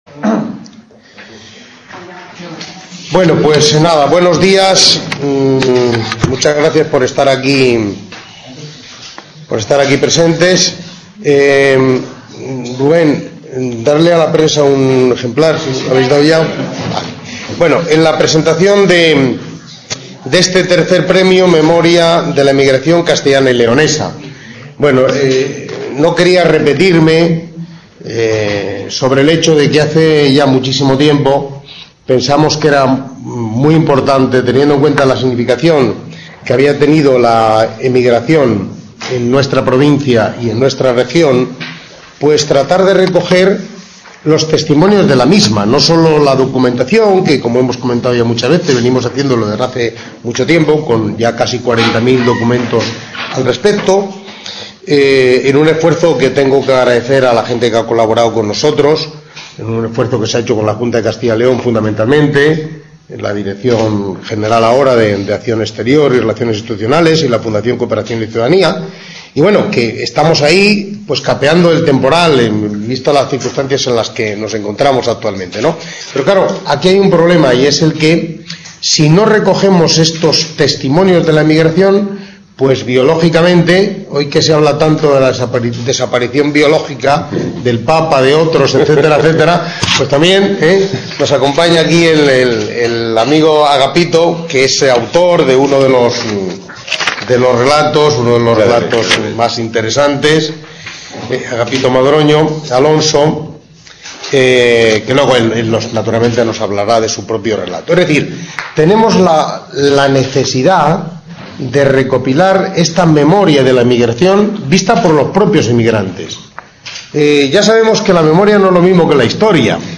Asig: Reunion, debate, coloquio...